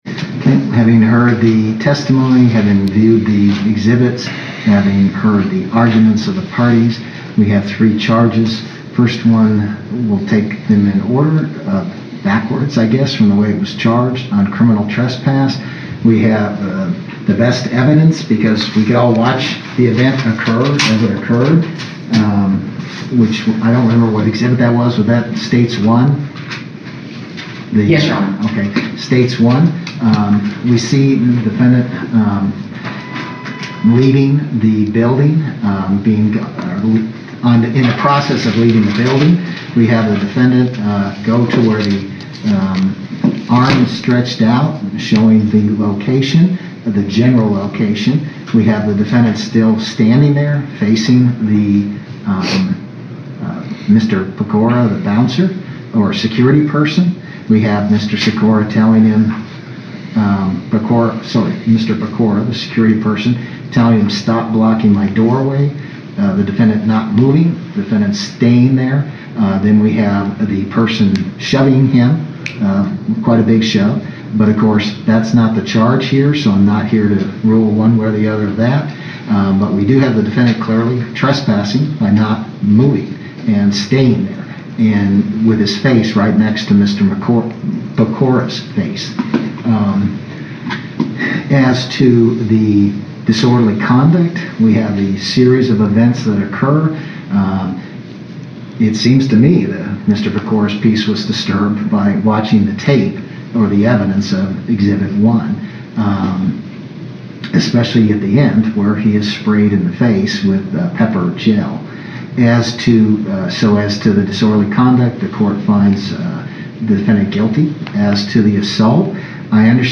Here is the verdict being read.